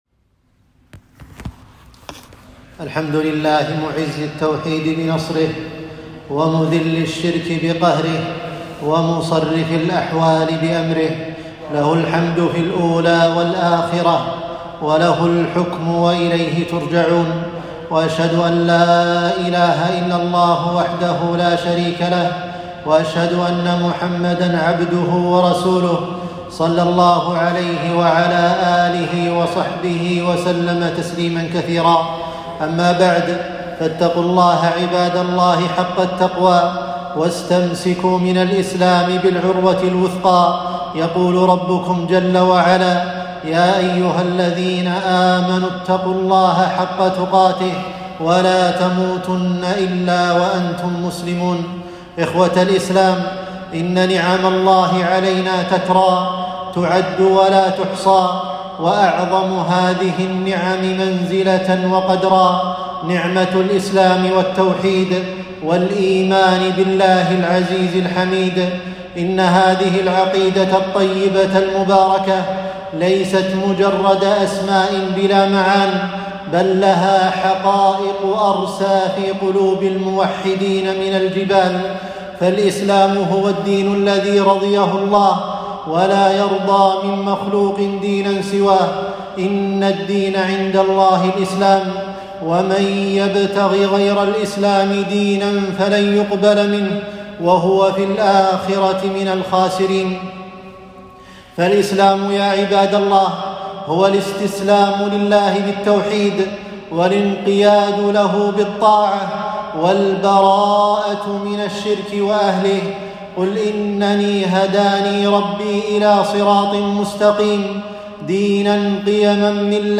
خطبة - صرخة موحد